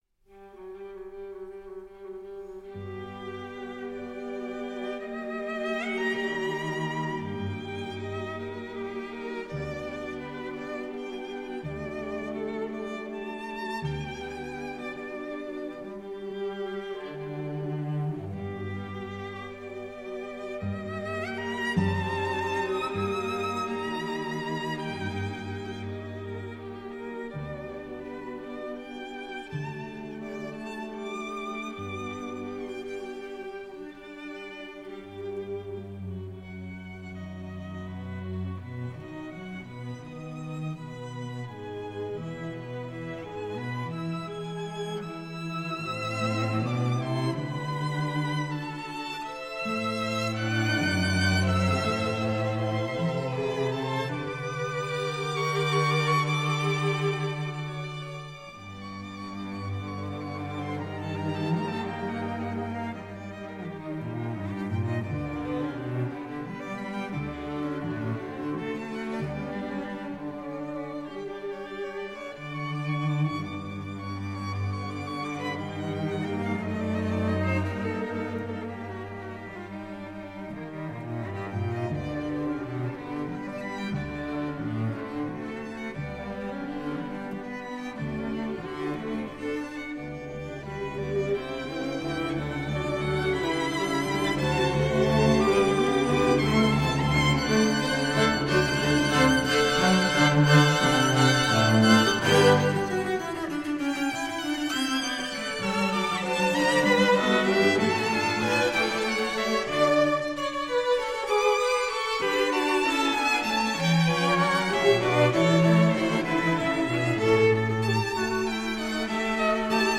String sextet
Most string sextets have been written for an ensemble consisting of two violins, two violas, and two cellos.